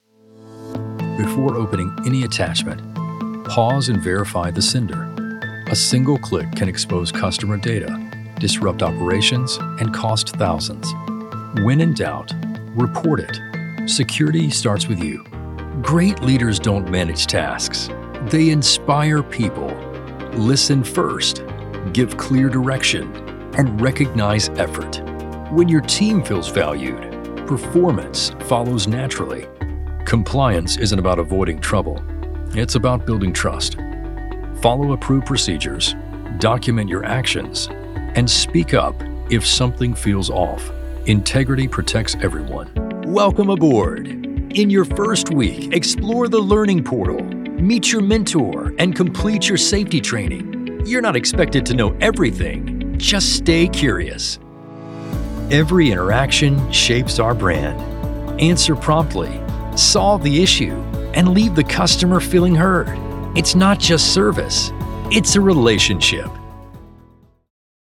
Conversational Male Voice-Commercial, Corporate Narration & E-Learning
Corporate Narration- Warm, trustworthy narration ideal for corporate videos, tech explainers, and brand messaging.
Neutral, Southern, Mid-western